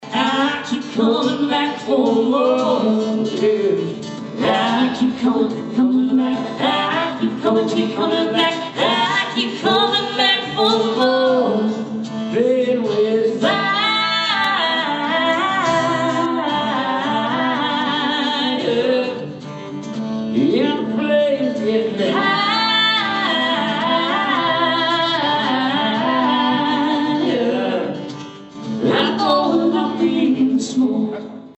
center stage at the Emporia Granada Theatre.
American Country Music Duo
husband and wife duo